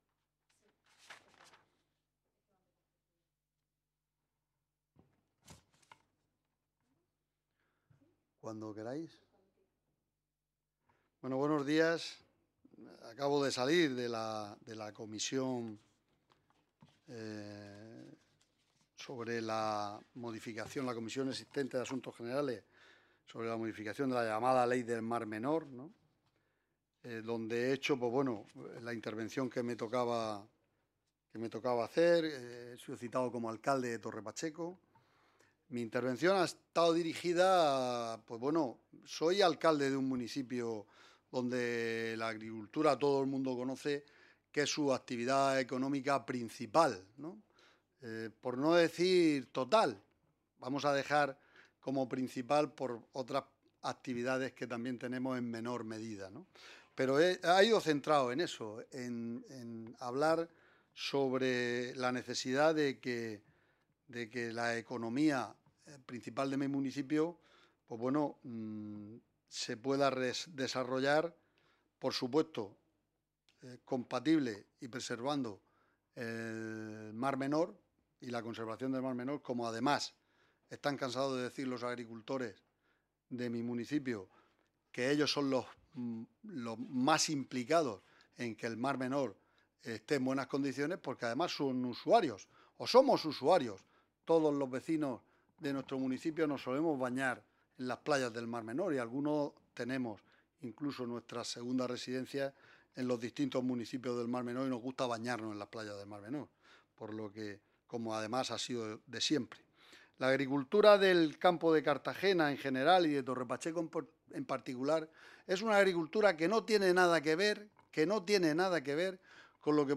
Ruedas de prensa posteriores a la Comisión de Asuntos Generales e Institucionales, de la Unión Europea y Derechos Humanos | Asamblea Regional de Murcia
Pedro Ángel Roca Tornel, alcalde del Ayuntamiento de Torre Pacheco Joaquín Gabriel Zapata García, alcalde del Ayuntamiento de La Unión Grupo Parlamentario Popular Grupo Parlamentario Socialista Grupo Parlamentario Vox